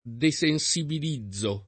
vai all'elenco alfabetico delle voci ingrandisci il carattere 100% rimpicciolisci il carattere stampa invia tramite posta elettronica codividi su Facebook desensibilizzare v.; desensibilizzo [ de S en S ibil &zz o ]